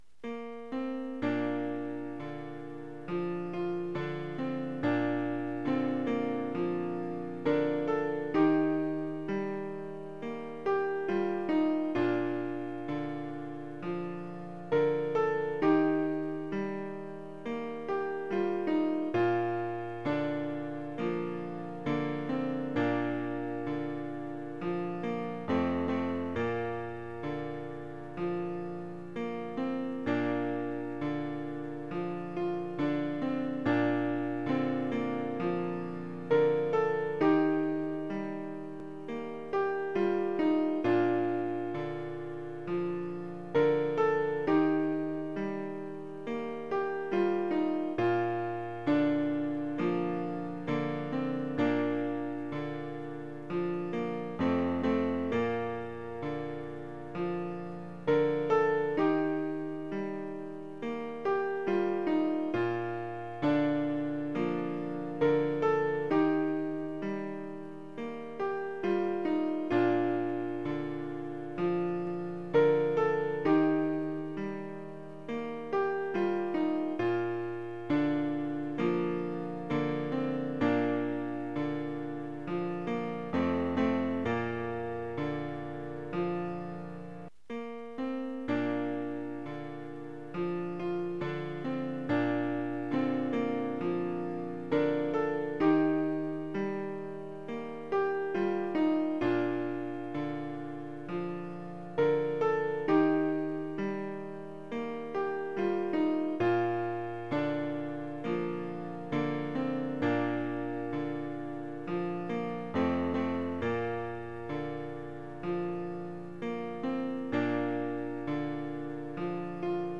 In spite of the general busyness of life a few of us have written more songs for worship at Wascana Fellowship.
O My Shepherd – The piece of Celtic music this originally comes from had a slower tempo than this reworking.